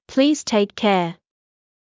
ｲｯﾄ ｳｫﾝﾄ ﾋﾞｰ ﾛﾝｸﾞ